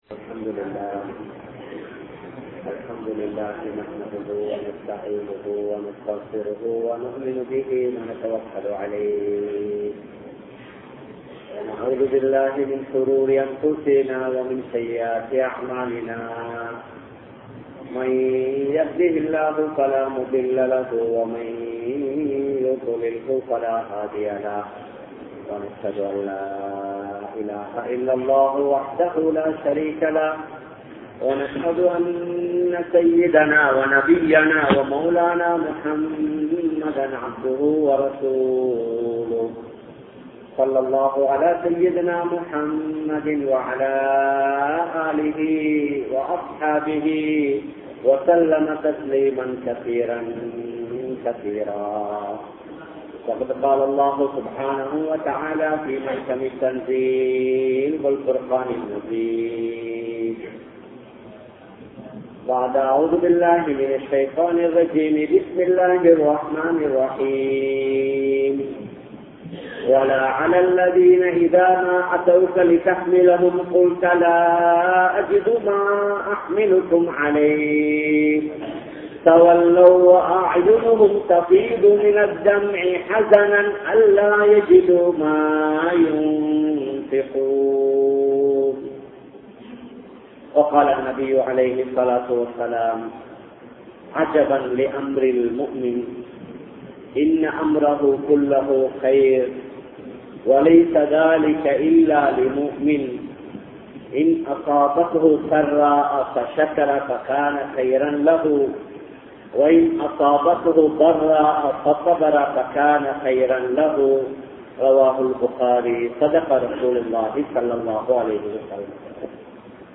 Sahaba Pengalin Thiyaahangal (ஸஹாபா பெண்களின் தியாகங்கள்) | Audio Bayans | All Ceylon Muslim Youth Community | Addalaichenai
Colombo, GrandPass Markaz